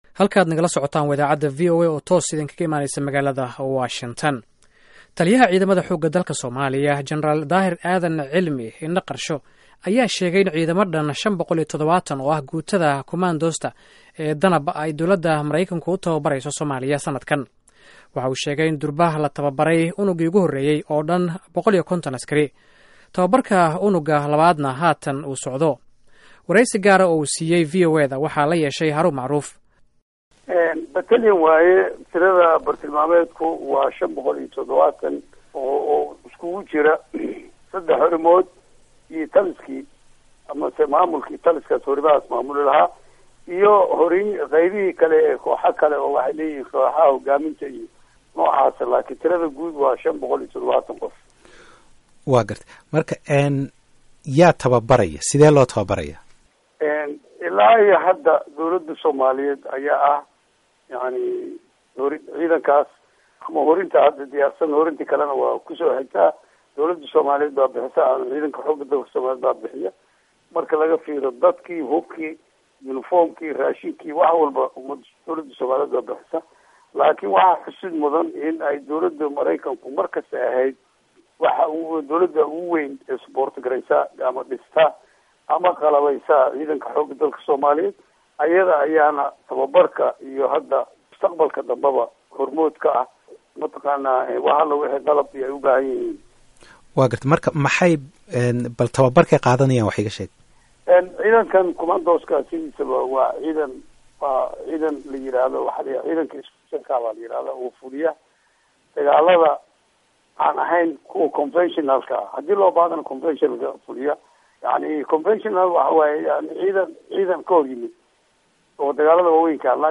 Dhageyso wareysiga General Indha Qarsho